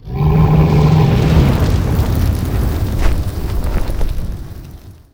CosmicRageSounds / wav / general / combat / creatures / dragon / bellow.wav
bellow.wav